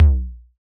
Index of /musicradar/retro-drum-machine-samples/Drums Hits/Tape Path B
RDM_TapeB_SY1-Tom03.wav